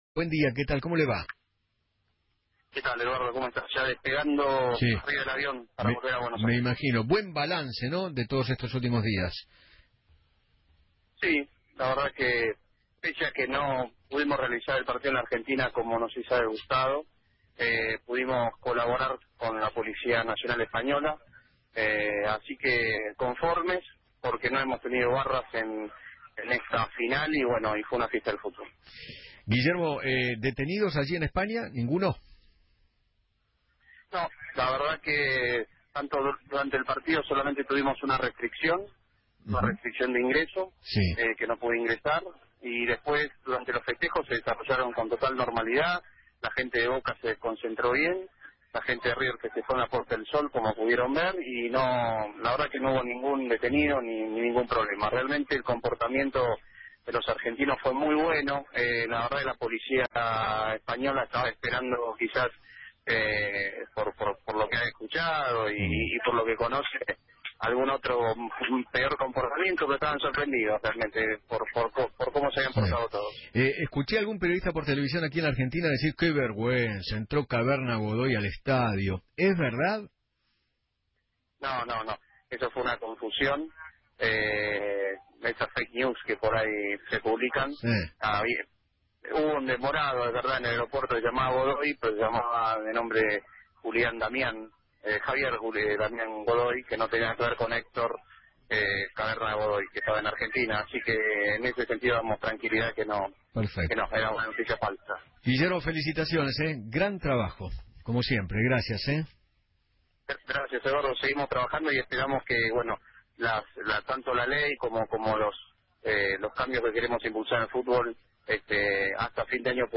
Guillermo Madero, Director de Seguridad en Espectáculos Futbolísticos del Ministerio de Seguridad de la Nación, habló  en Feinmann 910 y dijo que “Pese a no poder realizar el partido en Argentina, pudimos colaborar con la policía española, conformes porque no hemos tenido barras en la final y fue una fiesta del fútbol.